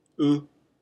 “u” is pronounced “ooh,” like saying “ooh ahh” when fireworks are going off.
u-hiragana.mp3